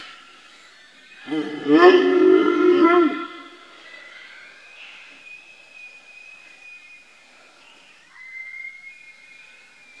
Вой волка и свист